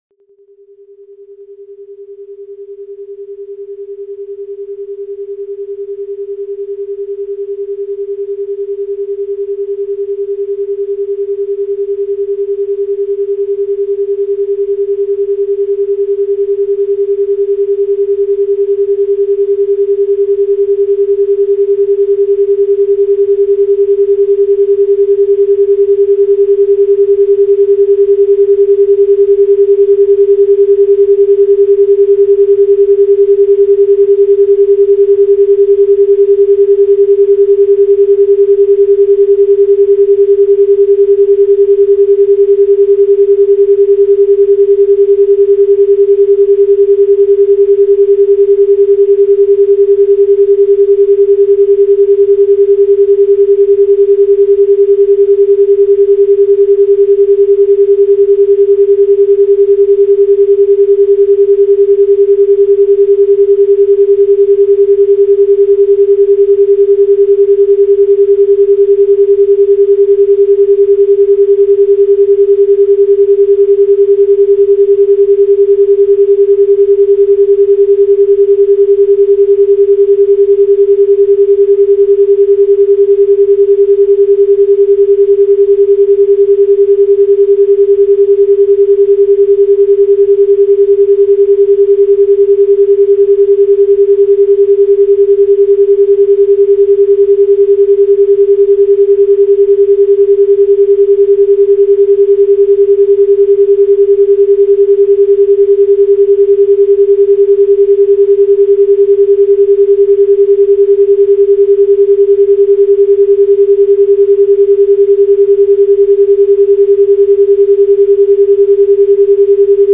На этой странице собраны звуки, которые ассоциируются с инопланетянами и пришельцами: странные сигналы, электронные помехи, \